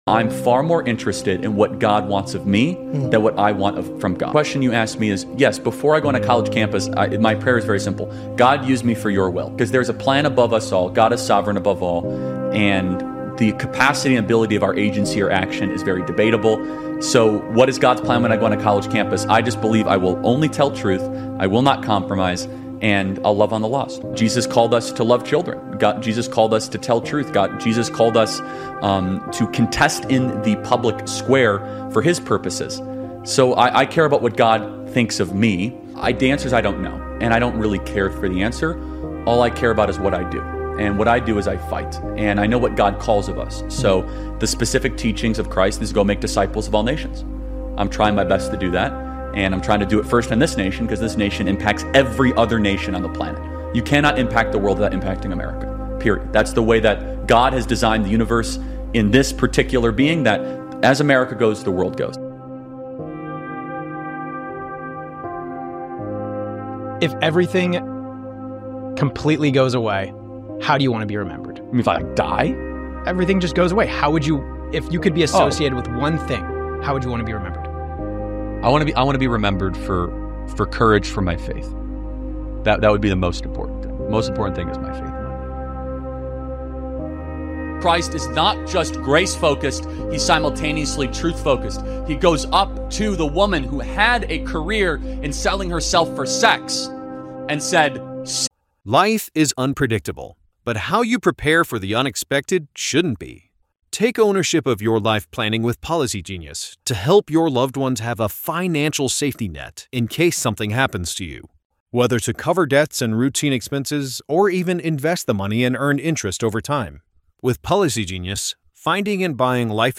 In this electrifying episode, conservative leader and Turning Point USA founder Charlie Kirk delivers a powerful message on the enduring legacy of legends who never truly die. With his signature passion and unyielding conviction, Kirk explores how icons of history, culture, and conviction live on through their impact, inspiring generations to rise, fight, and carry the torch.